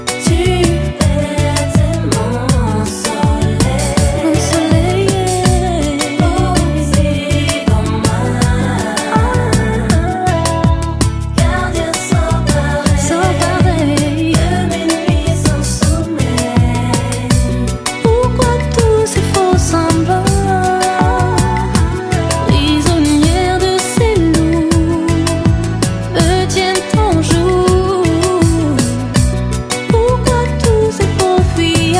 Musique Zouk